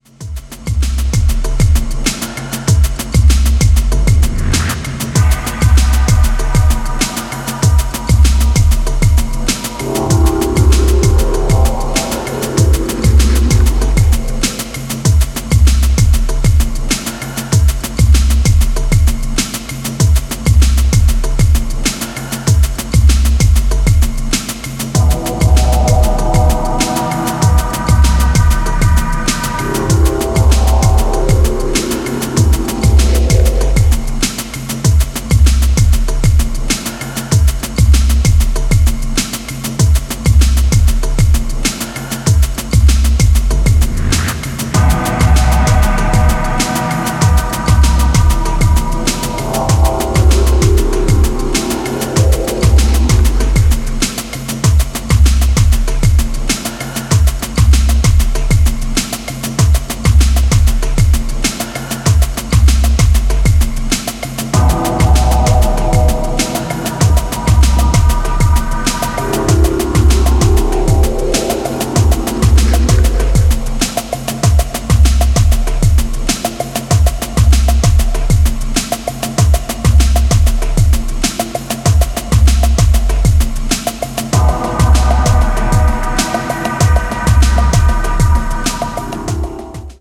ヘヴィ・トライバルな97BPM
いずれも極めてディープな存在感を放つトラック。”